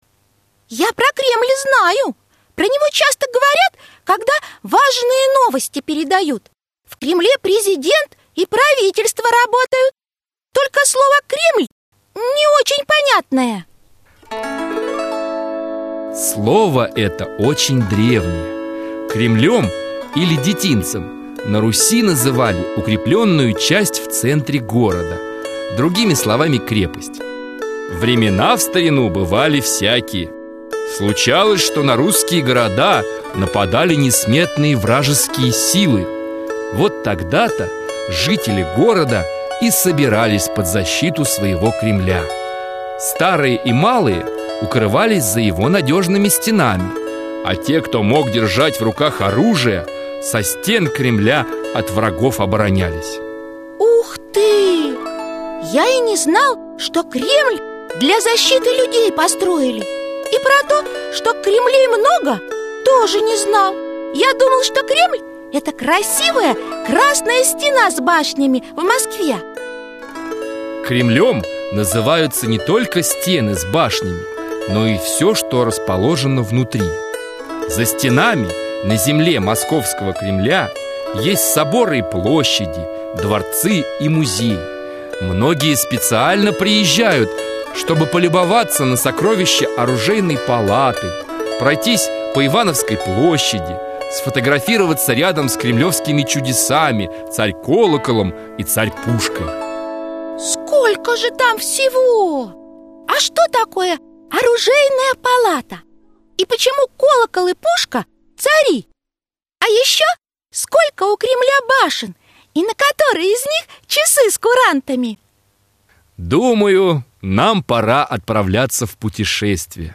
Аудиокнига Московский Кремль | Библиотека аудиокниг